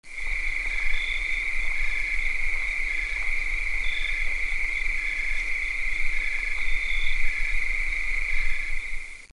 Grilli
Canto di gruppo di grilli vari. Suono ambiente.
CRICKS2.mp3